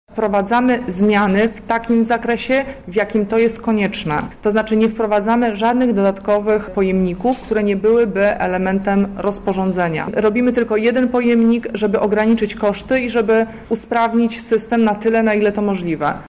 -mówi Krzysztof Żuk, prezydent miasta Lublin